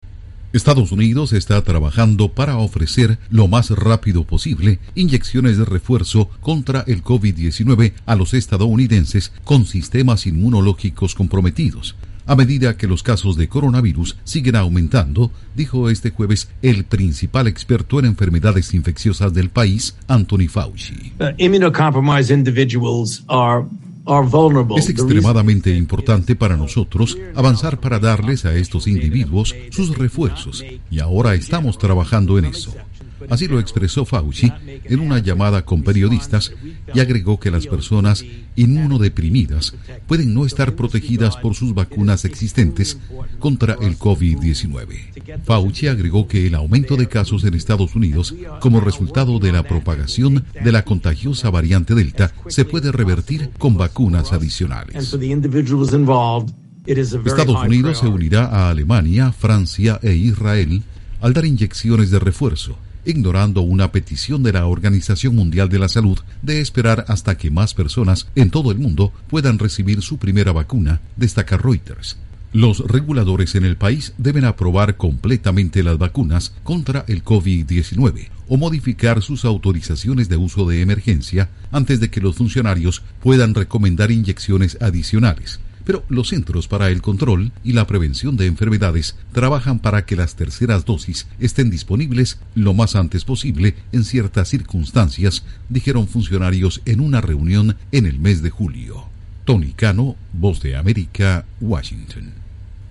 EE.UU. planea dar inyecciones de refuerzo contra COVID-19 para ciudadanos en riesgo dice Fauci. Informa desde la Voz de América en Washington